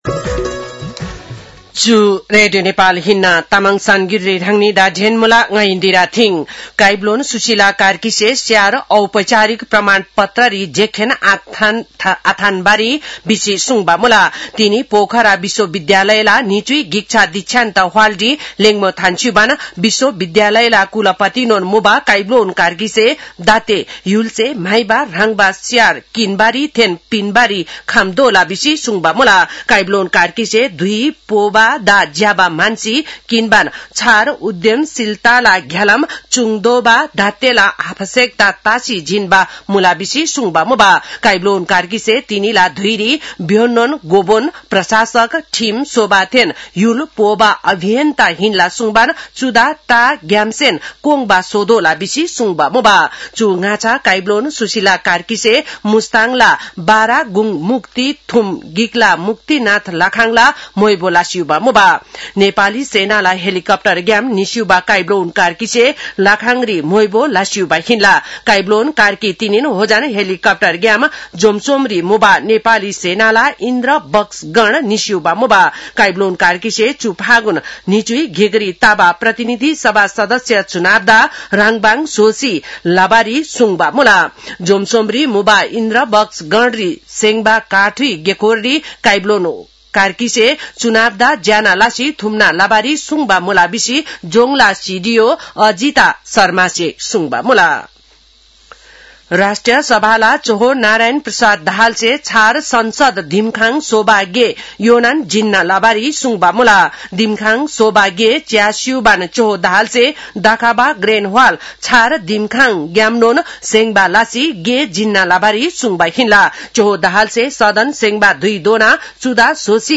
तामाङ भाषाको समाचार : ८ फागुन , २०८२
Tamang-news-11-08.mp3